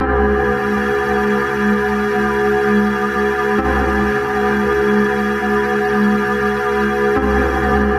描述：乱用音频样本，为神秘的游客创造氛围的绘画
Tag: 60 bpm Ambient Loops Synth Loops 1.35 MB wav Key : Unknown